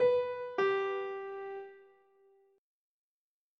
autodarts triple 16 alternative piano Meme Sound Effect
autodarts triple 16 alternative piano.mp3